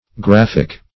Graphic \Graph"ic\ (gr[a^]f"[i^]k), Graphical \Graph"ic*al\